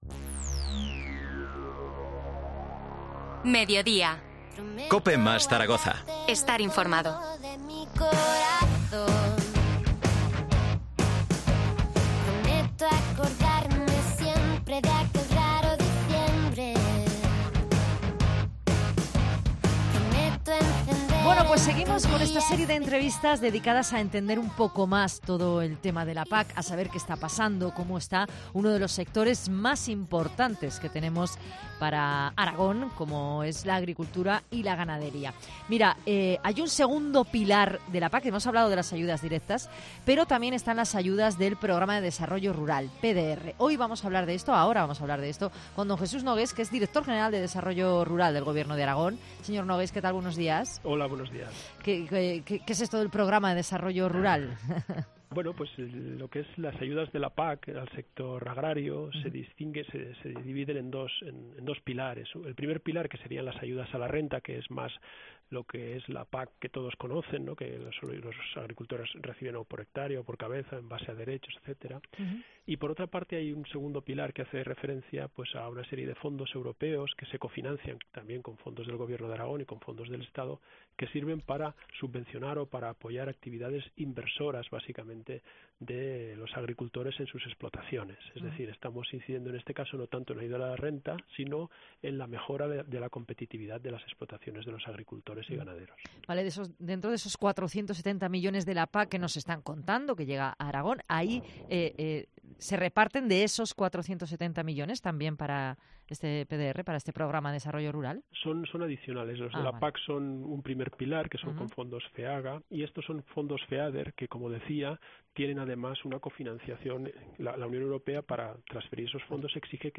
Entrevista a Jesús Nogués, director general de Desarrollo Rural